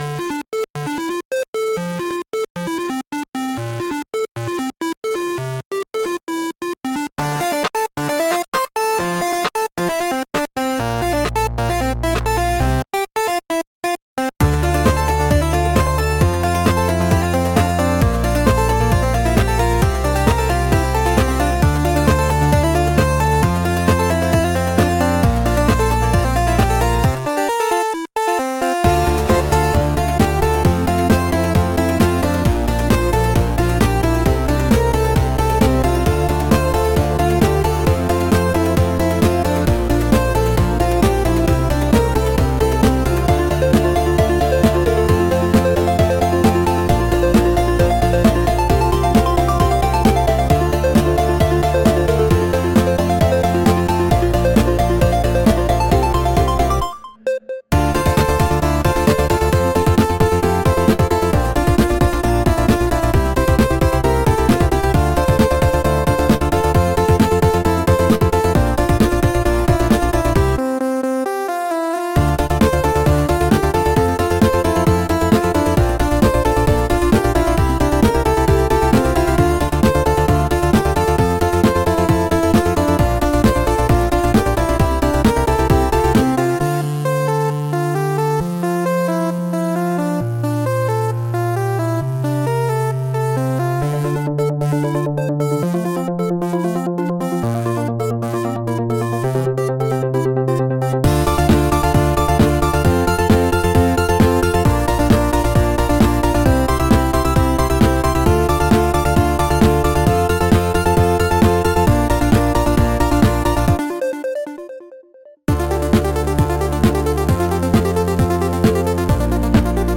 Genre: Digicore Mood: Video Game Editor's Choice